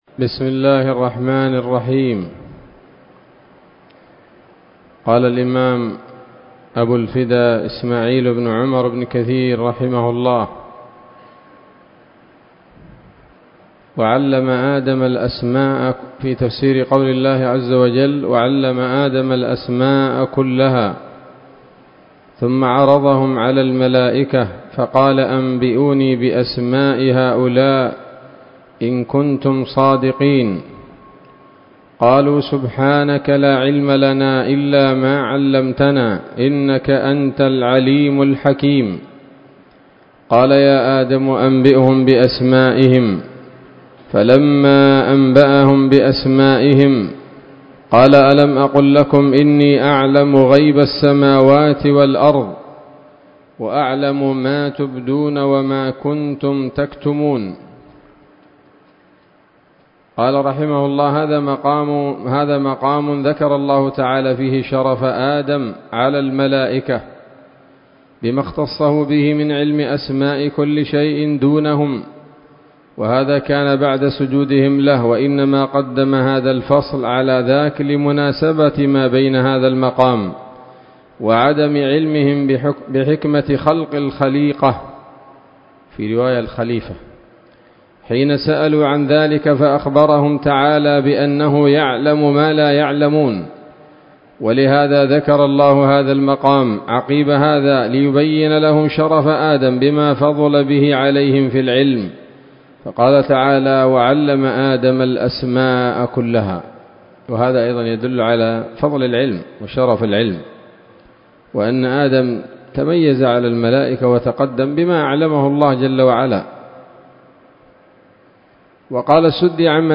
الدرس السادس والثلاثون من سورة البقرة من تفسير ابن كثير رحمه الله تعالى